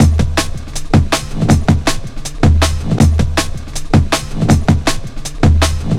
Index of /90_sSampleCDs/Zero-G - Total Drum Bass/Drumloops - 1/track 12 (160bpm)